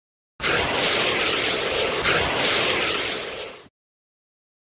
Fire Extinguisher 2 Sound Effect Free Download
Fire Extinguisher 2